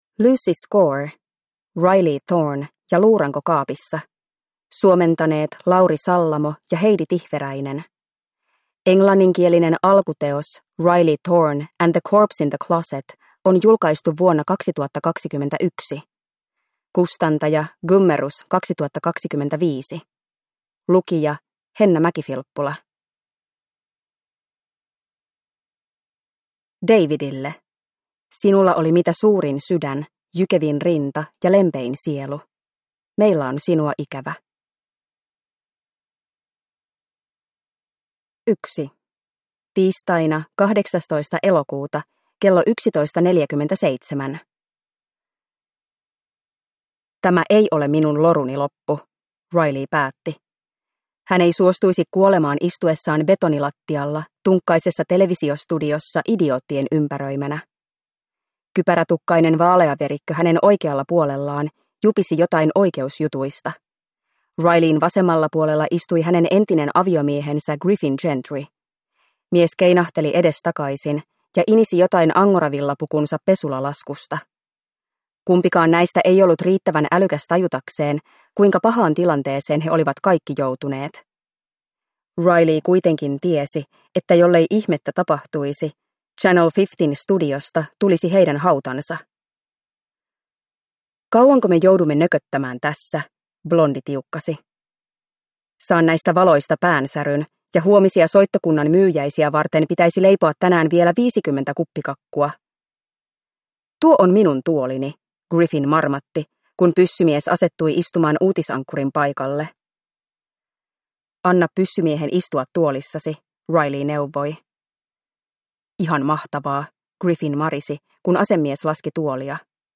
Riley Thorn ja luuranko kaapissa (ljudbok) av Lucy Score